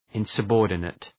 Shkrimi fonetik{,ınsə’bɔ:rdənıt}
insubordinate.mp3